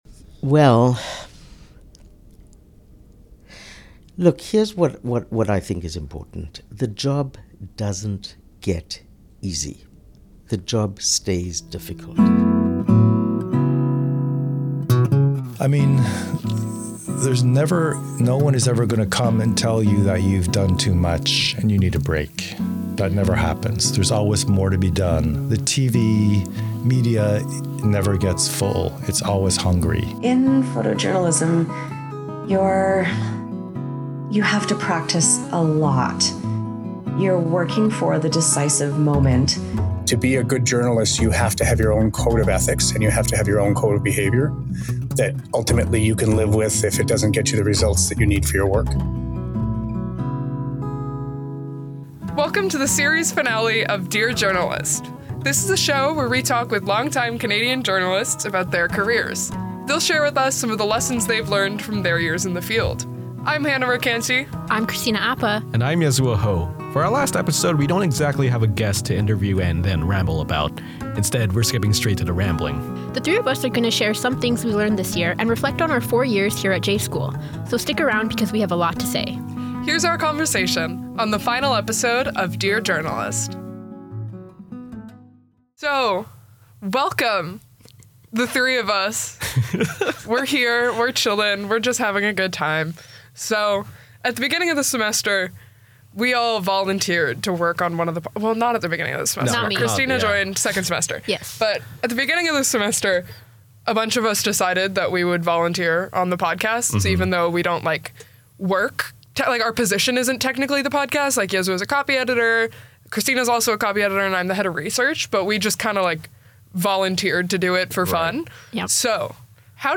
In this final episode of Dear Journalist, the hosts are the ones giving their “pearls” of wisdom. We discuss our history with podcasting, our experience at the Review, why we chose to enroll in TMU’s journalism program, and some things we wish we heard before enrolling in the program.